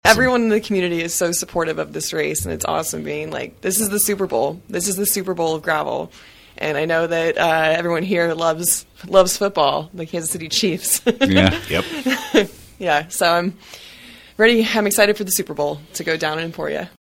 ‘This is the Super Bowl of gravel:’ Past Unbound champions reflect on experiences during KVOE Morning Show interview